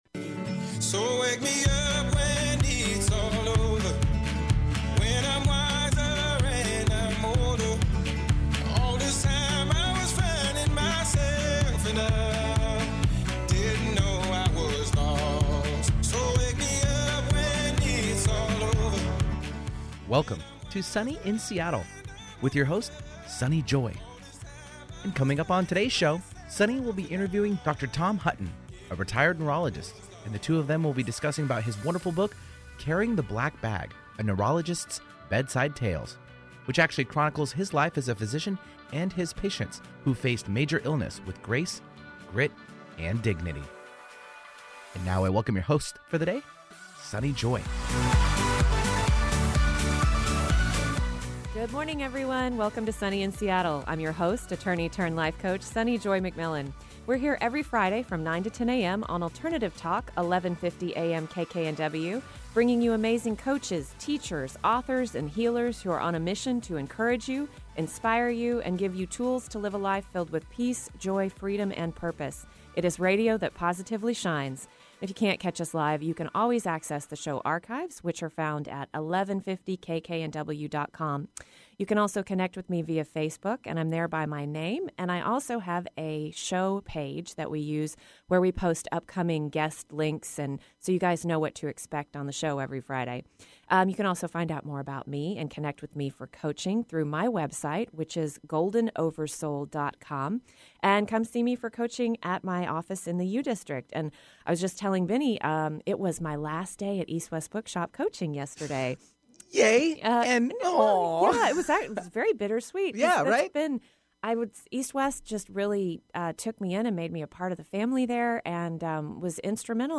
We also had well-informed callers who provided thoughtful observations and questions.